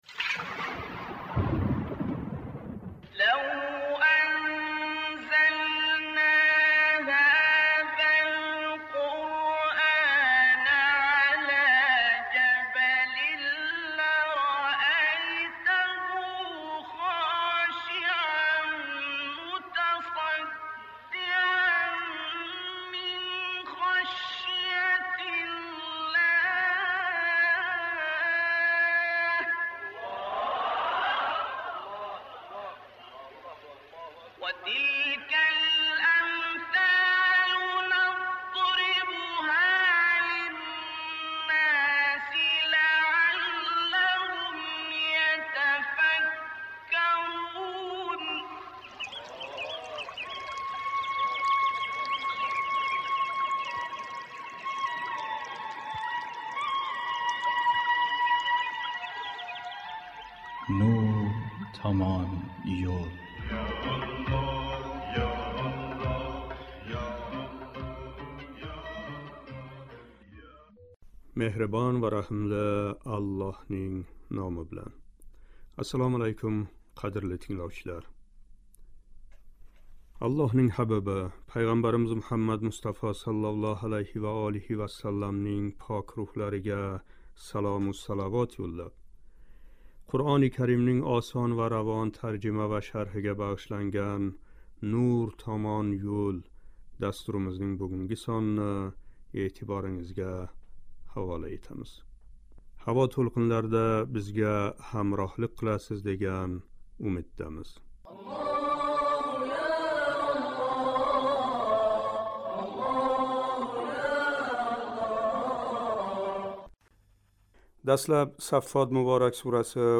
« Саффот " муборак сураси 12-21-ояти карималарининг шарҳи. Дастлаб “Саффот” муборак сураси 12-15- ояти карималарининг тиловатига қулоқ тутамиз: